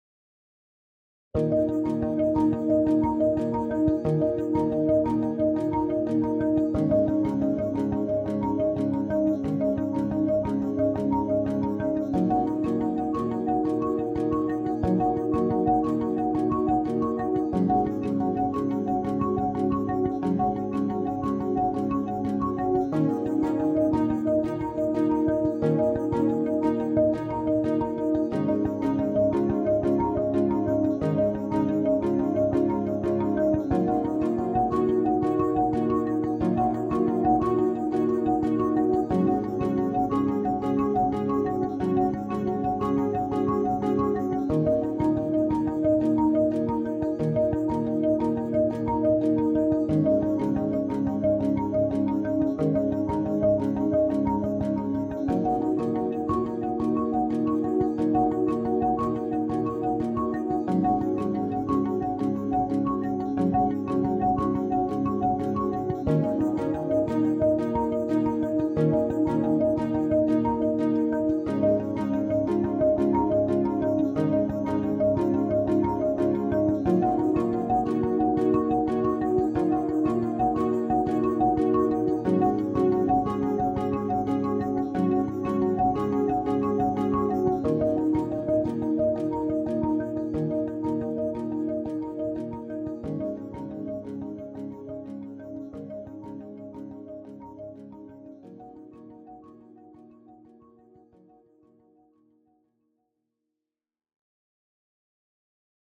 Synth ambient track for puzzle.